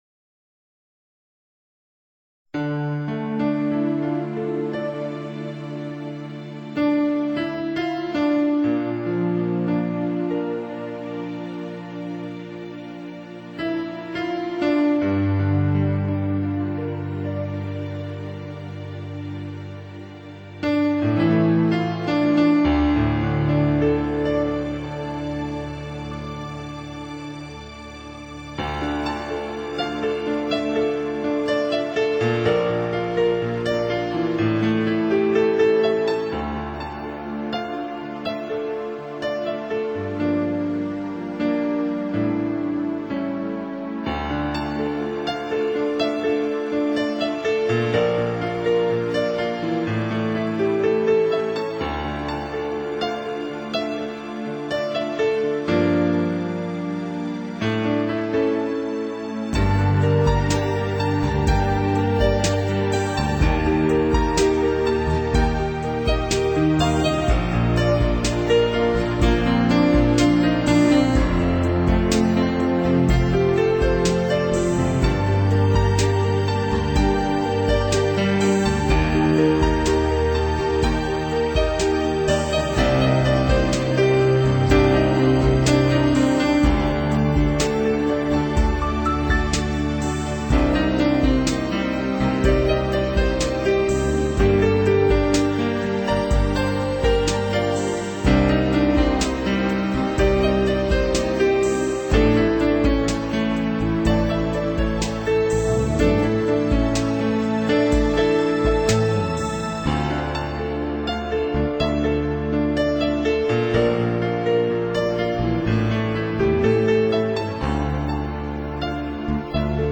...本专辑中，他们以简单流畅的旋律，加入大自然意象与流行元素，使人悠然神往。
音乐运用了排笛，钢琴和大量的电子键盘音，一种纯的音乐，一种享受的感觉。